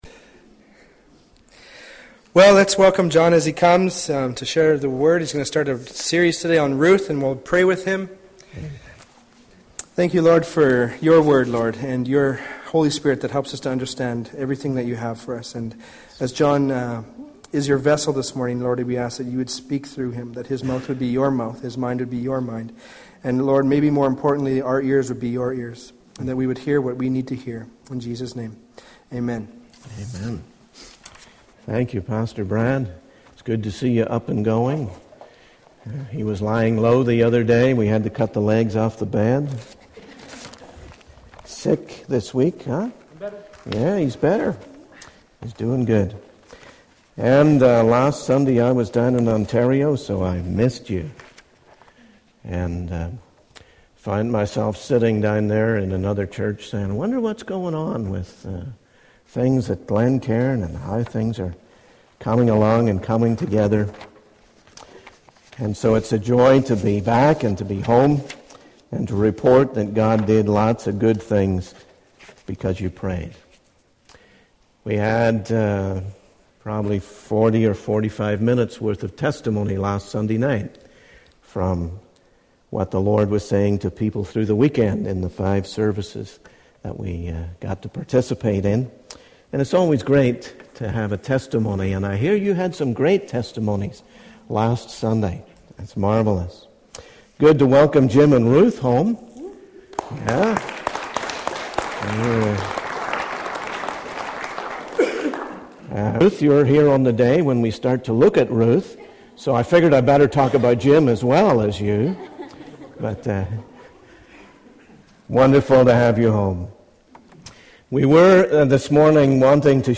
The video mentioned in the sermon transcript is not shown in this particular sermon, but it is promised to be shown next week. The speaker expresses gratitude for how God has used and blessed them despite encountering various challenges along the way.